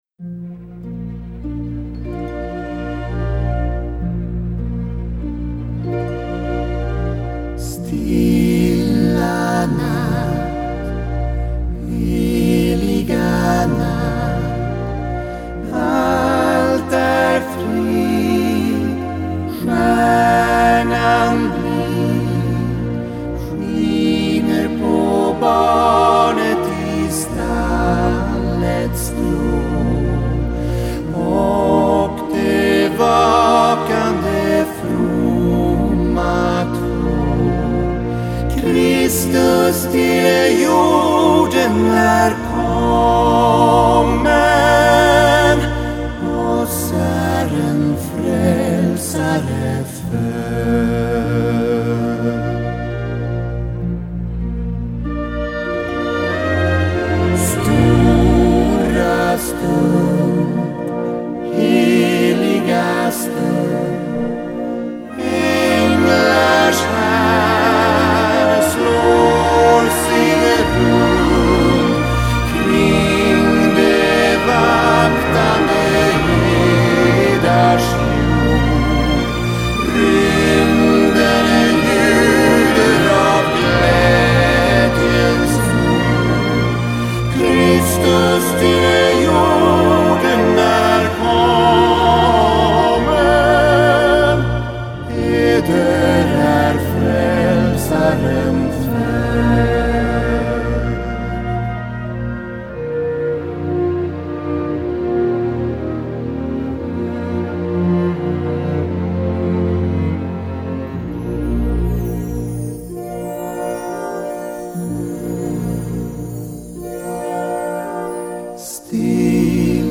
En klassisk akustisk show där vi tar med den sittande publiken till vår vinterstuga i fjällen och skålar i glögg och läser julhälsningar
från publiken samt sjunger svenska klassiska julsånger som ”Stilla natt” med komp av stråkorkester